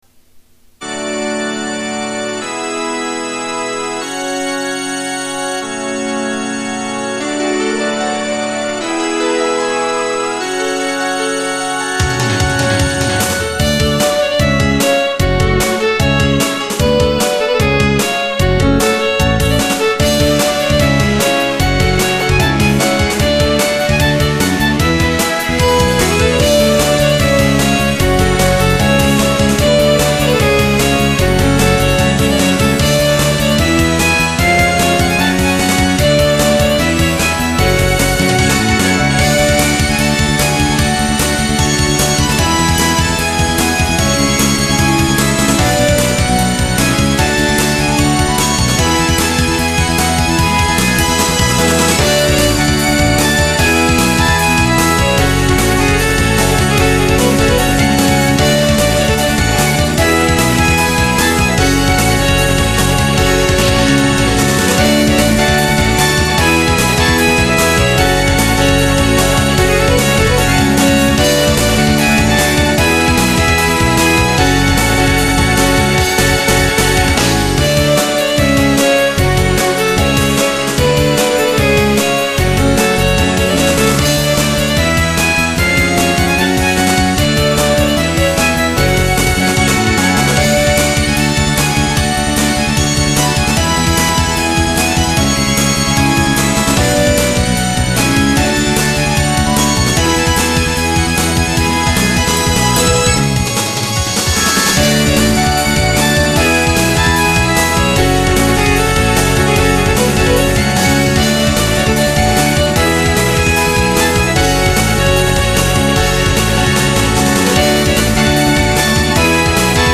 SoundEngine   Cherry Classical Rock
タイトルにあるような聖譚曲では決してありませんが、楽器構成などに 少しクラシカルな要素を取り入れてみました。